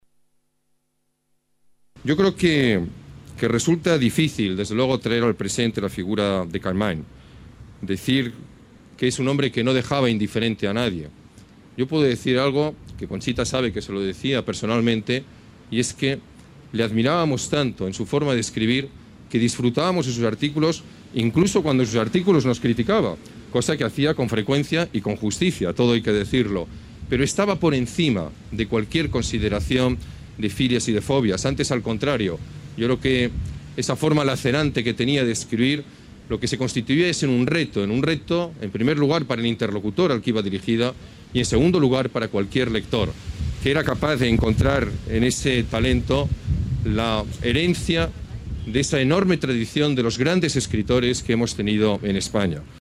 Nueva ventana:Declaraciones del alcalde, Alberto Ruiz-GAllardón: Glorieta Jaime Campmany